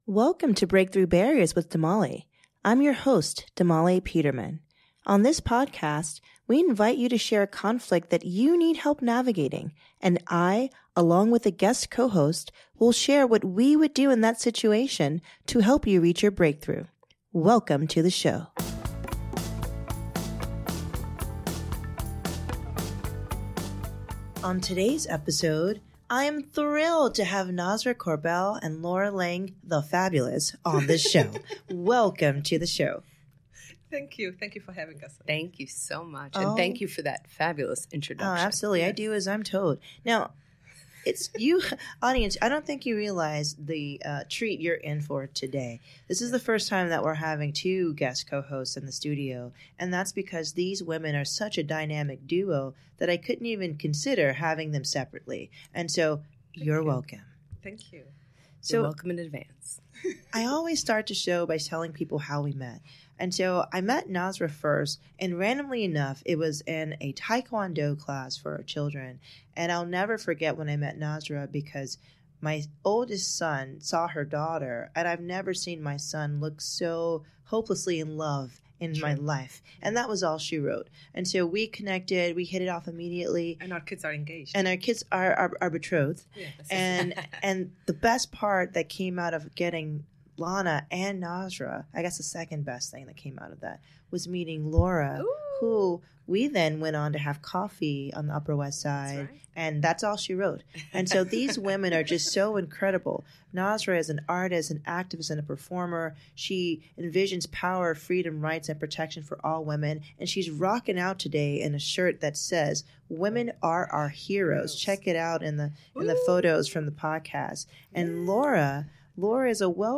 This dynamic duo brought so much charisma to the studio and a song for our listeners!